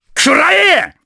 Baudouin-Vox_Attack4_jp.wav